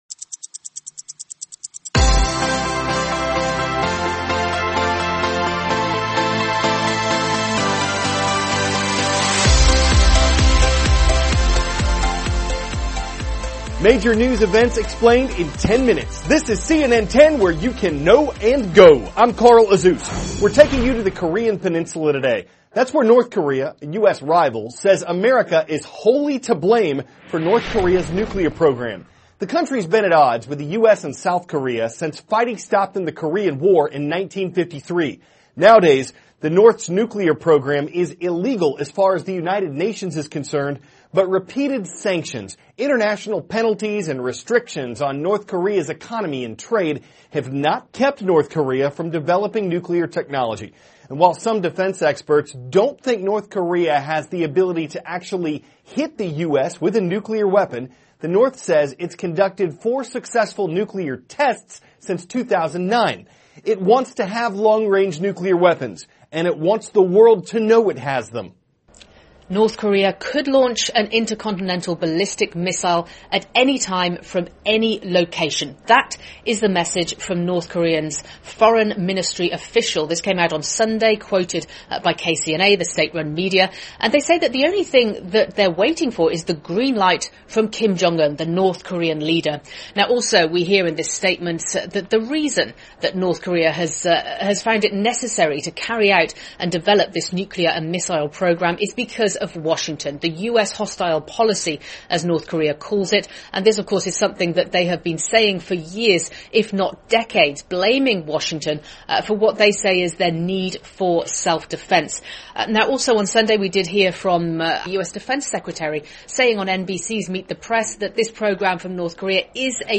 (cnn Student News) -- January 10, 2017 The U.S. Position on a Potential North Korean Threat; How the Senate Confirmation Process Works; Where Airplanes Make Their Last Landing *** THIS IS A RUSH TRANSCRIPT.